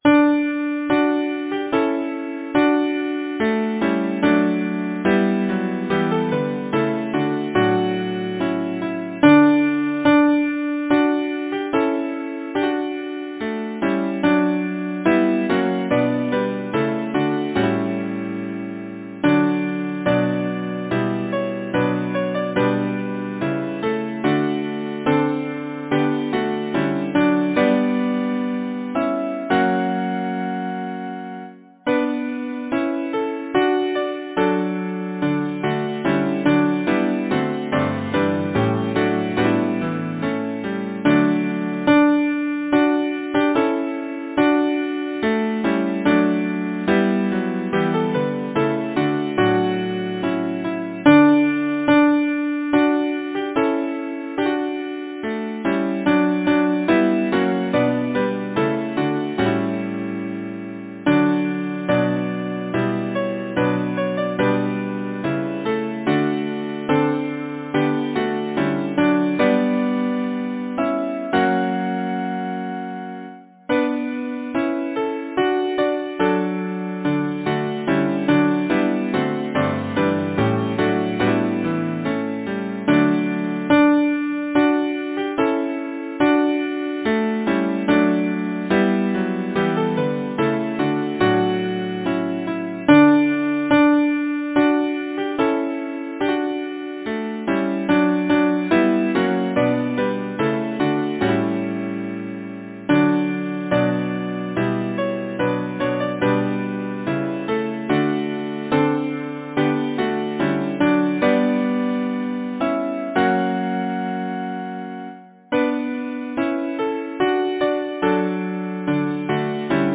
Title: Joy of my earliest days Composer: Henry A. Lambeth Lyricist: Carolina Oliphant Number of voices: 4vv Voicing: SATB Genre: Secular, Partsong
Language: Lowland Scots Instruments: A cappella